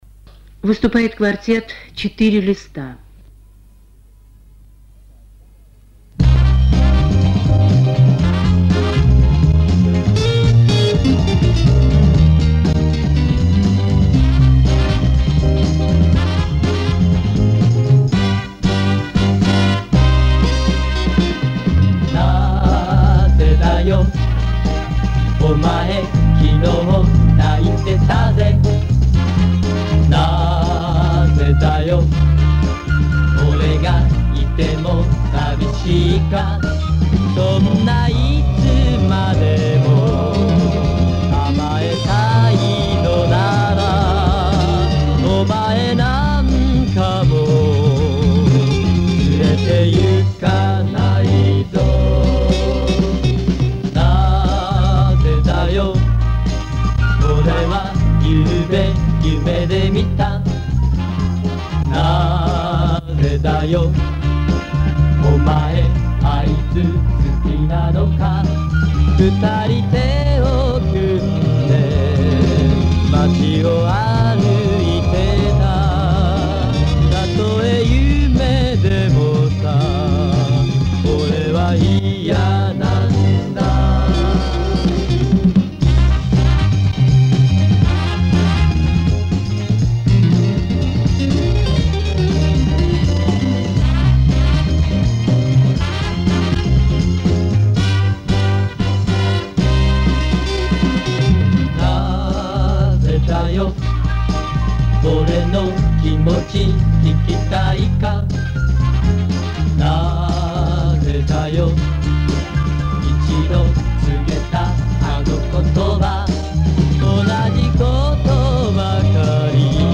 Это оцифровка бобины с записями 1972 -1975 годов.